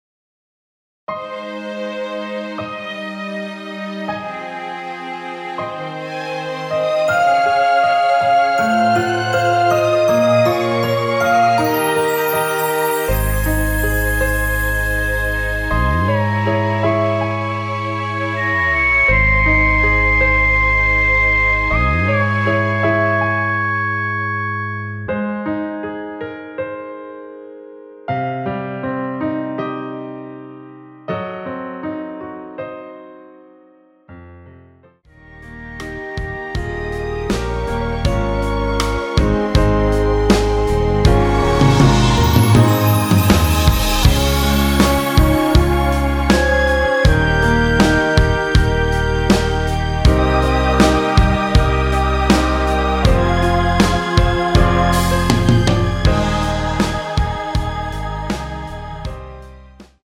원키에서(+5)올린 MR입니다.
Ab
앞부분30초, 뒷부분30초씩 편집해서 올려 드리고 있습니다.
중간에 음이 끈어지고 다시 나오는 이유는